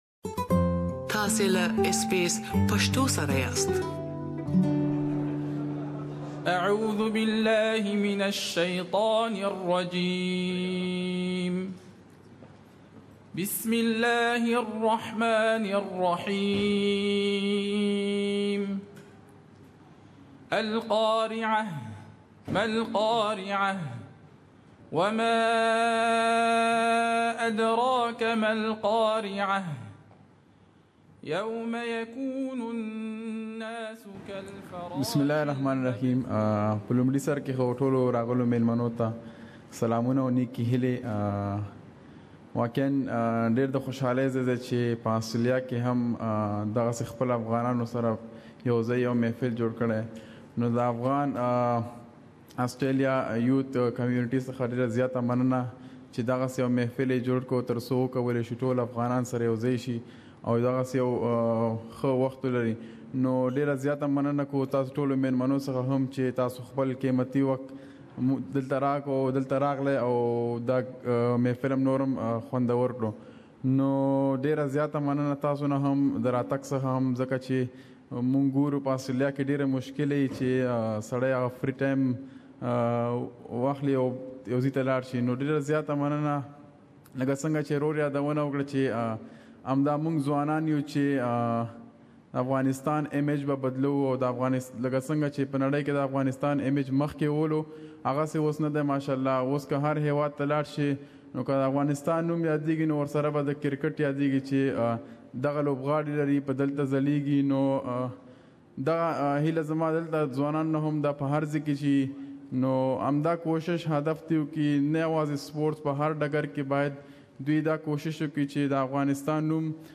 Rashid Khan giving speech at AAYA hosted event Source: AAYA